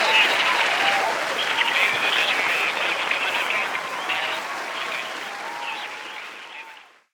cheering.ogg